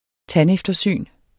Udtale [ ˈtan- ]